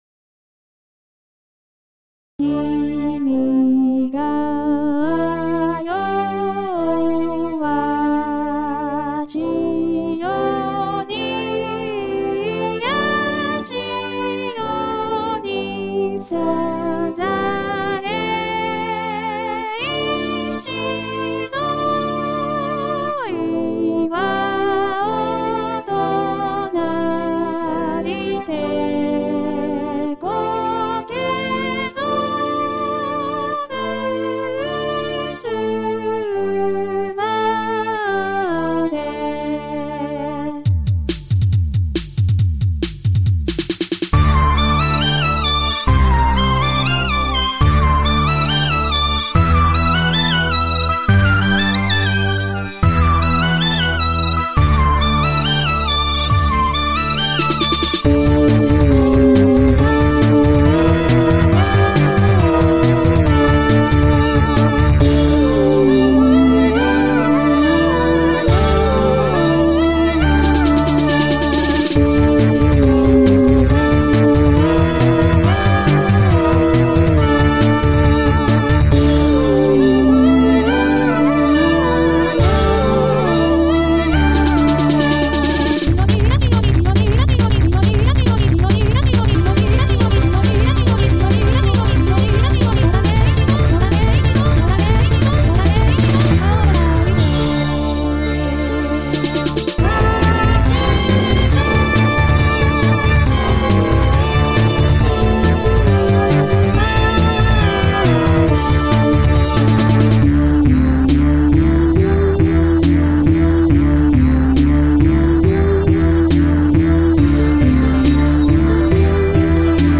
君力’”イ弋2007 日本人なら誰もが知っている歌のアレンジ。